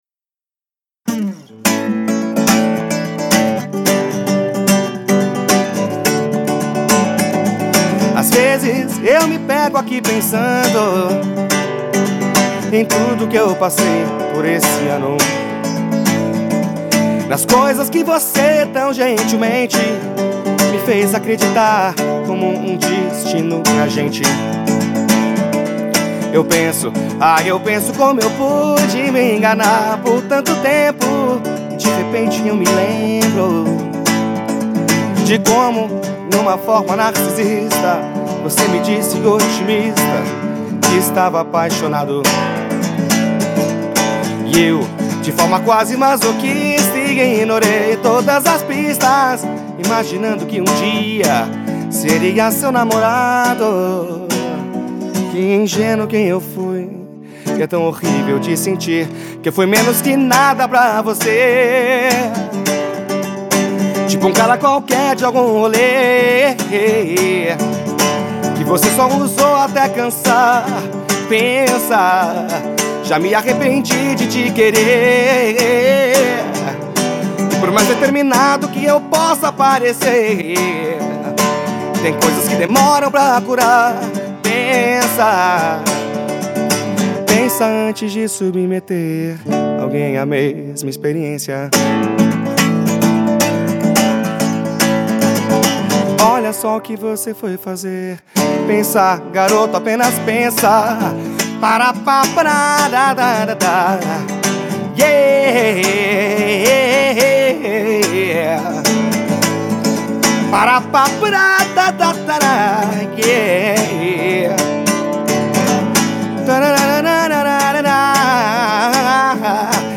EstiloMPB